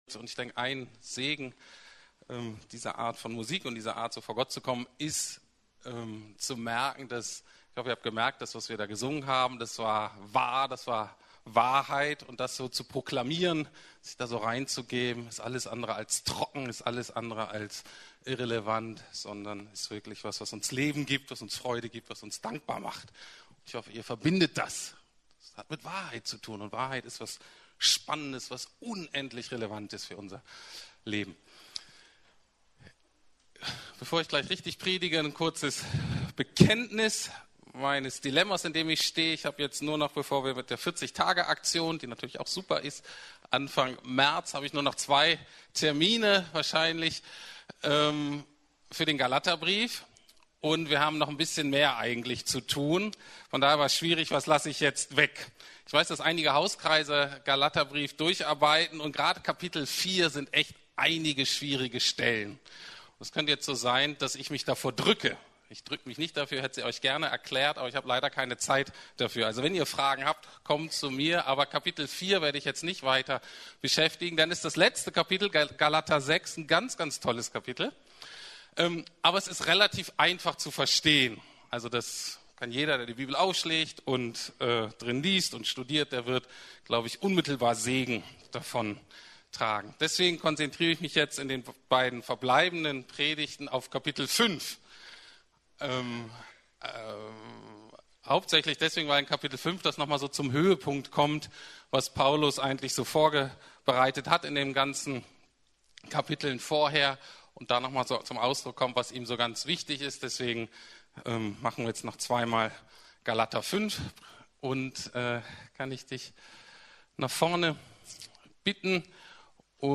Zur Freiheit berufen ~ Predigten der LUKAS GEMEINDE Podcast